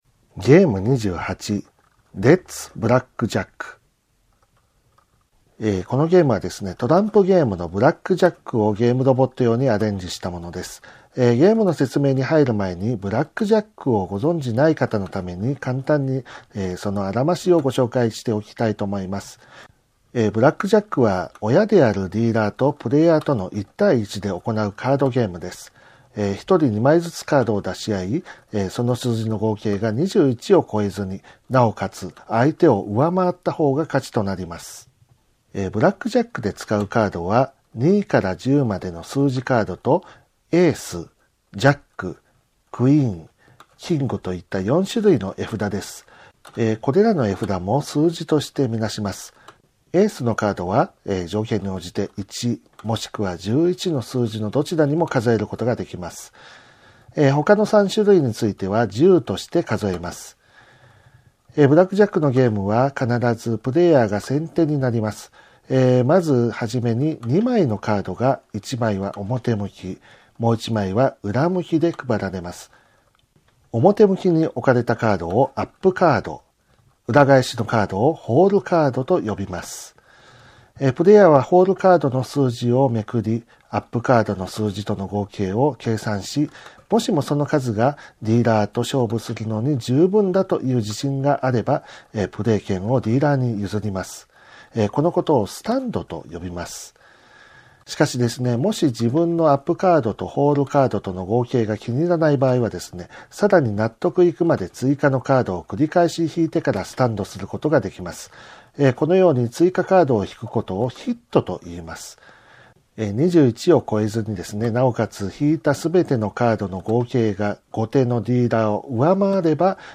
ゲームロボット５０の遊び方音声ガイド
遊び方の説明書は商品に同梱していますが、目の不自由な方にも遊んでいただけるよう音声による遊び方の説明をご用意しております。